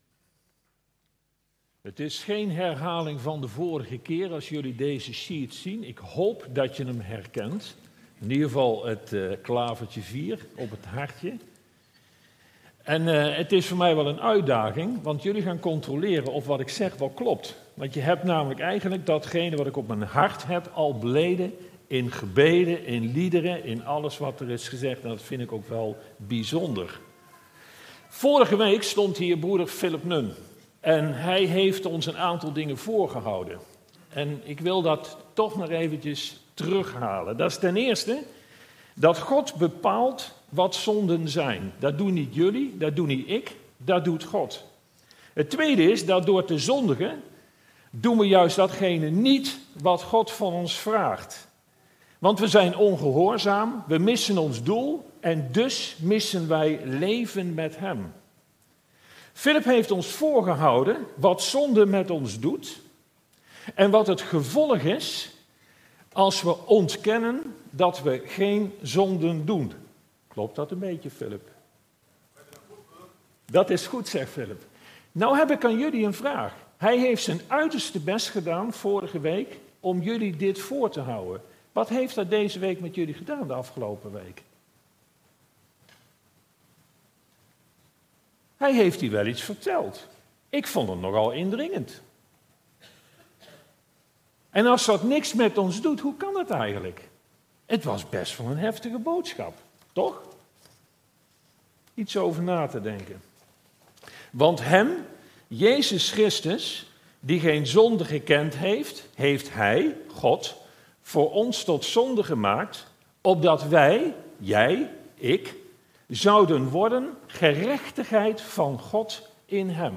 Toespraak 29 mei: Metamorfose, een groot wonder van Gods genade - De Bron Eindhoven